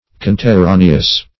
Meaning of conterraneous. conterraneous synonyms, pronunciation, spelling and more from Free Dictionary.
Search Result for " conterraneous" : The Collaborative International Dictionary of English v.0.48: Conterranean \Con`ter*ra"ne*an\, Conterraneous \Con`ter*ra"ne*ous\, a. [L. conterraneus; con- + terra country.]